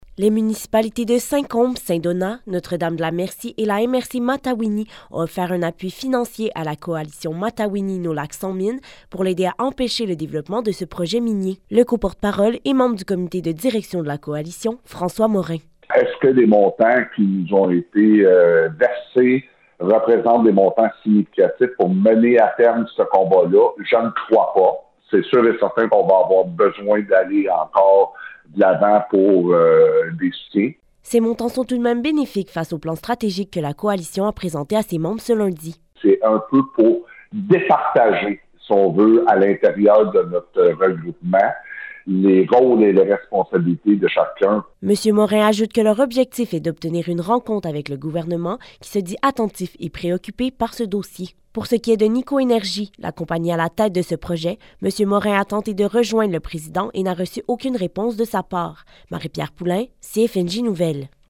Bulletin de nouvelles radio CFNJ